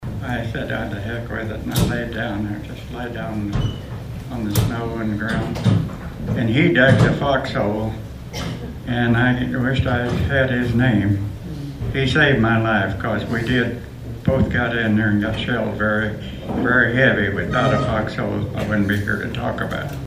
Stories were shared during a panel discussion Saturday at the American Legion.